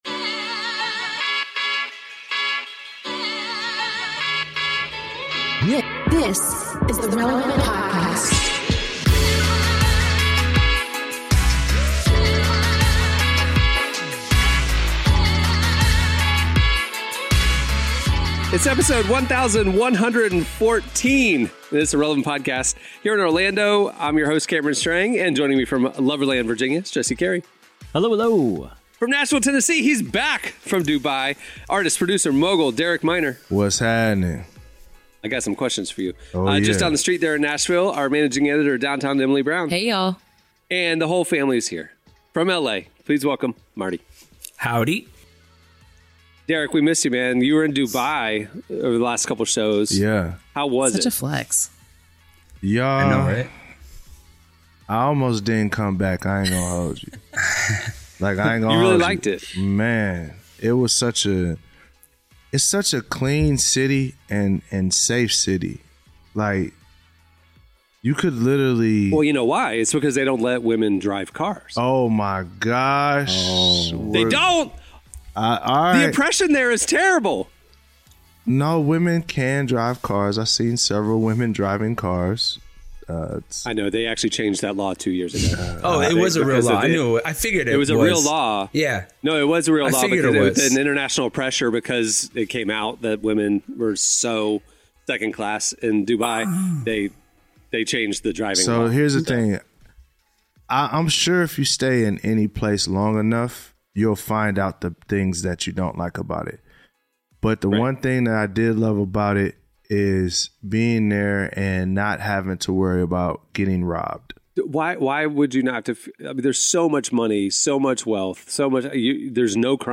Today we're joined by LA-based worship collective Mosaic MSC.